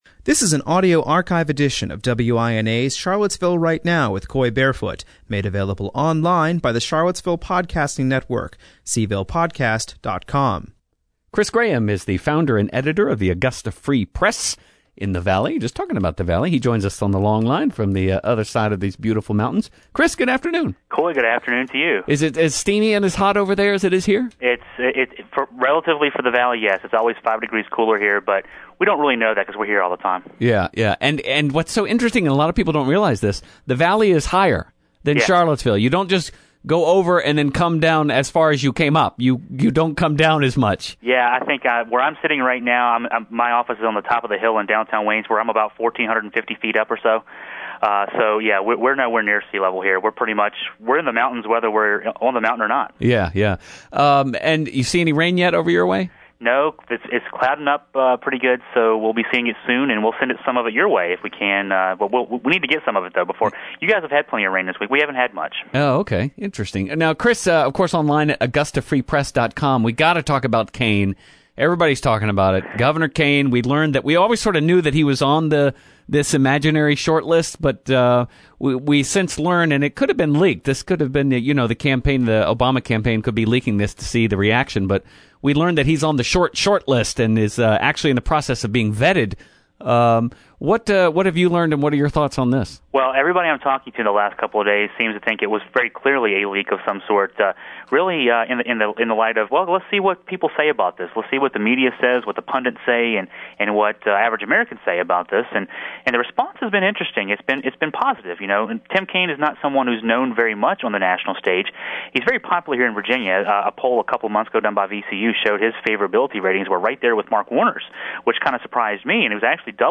Republican incumbent Congressman Virgil H. Goode, Jr. and Democratic Challenger Tom Perriello spoke at a Senior Statesmen of Virginia event held August 13th, 2008 at the Senior Center in Charlottesville. Following opening statements by the candidates, questions were taken from members of the audience.